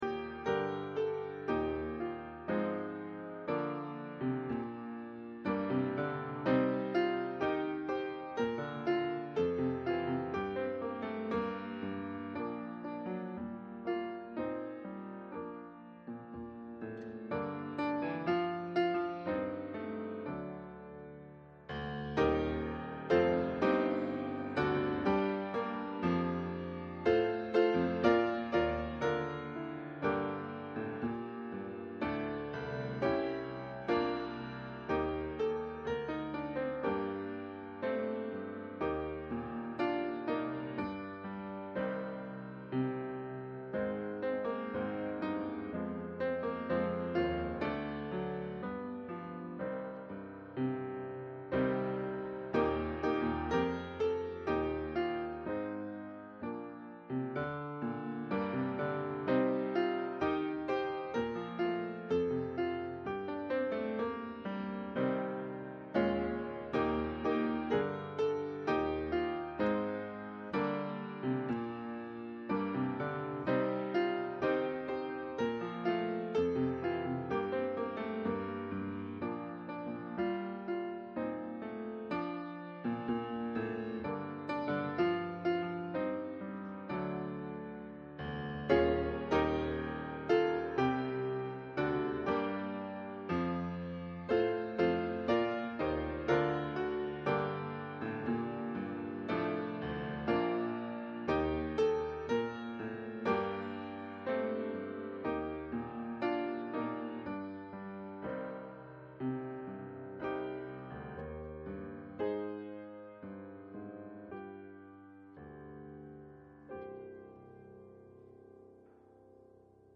Bible Text: Romans 21 | Public Reading of Holy Scripture
Service Type: Sunday Afternoon